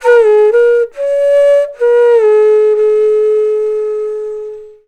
FLUTE-A07 -L.wav